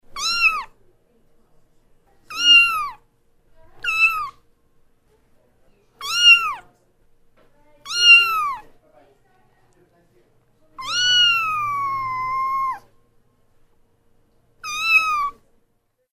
• Качество: 128, Stereo
звук котенка
мяуканье котика